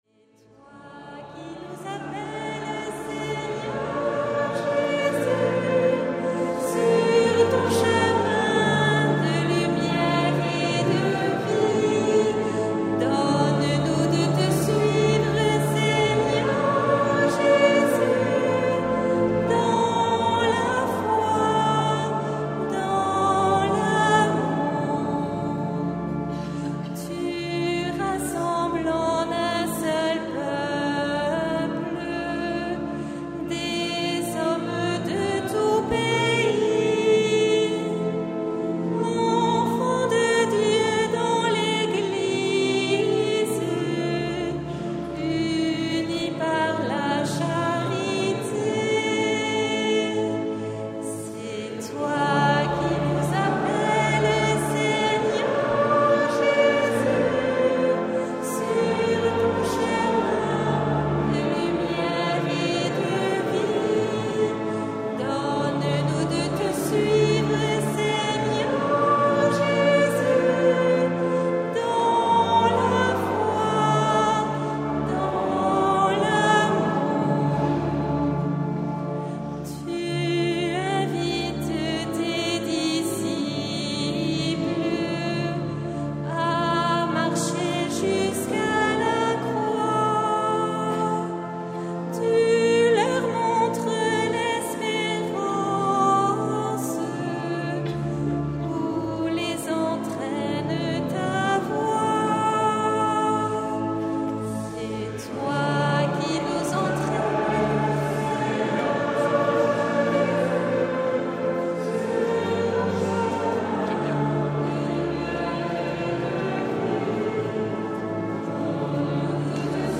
Messe de la solennité du Christ, Roi de l’univers présidée par Mgr Marc Aillet le 26 novembre 2023 en la cathédrale Sainte-Marie de Bayonne.